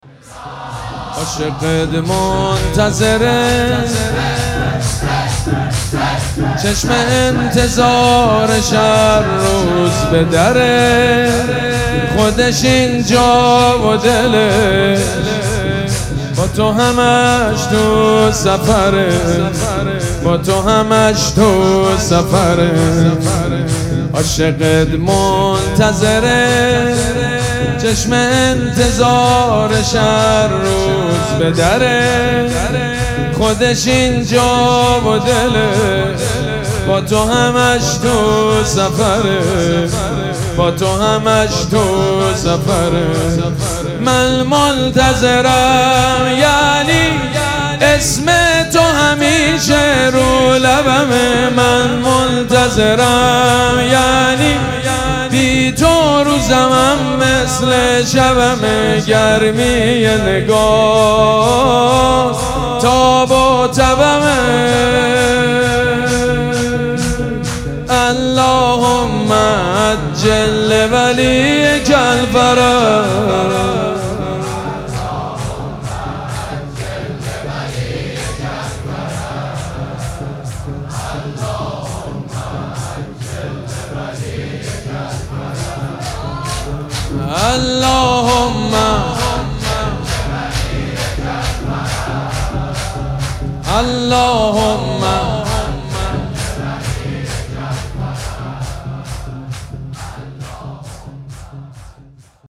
شب پنجم مراسم عزاداری دهه دوم فاطمیه ۱۴۴۶
حسینیه ریحانه الحسین سلام الله علیها
شور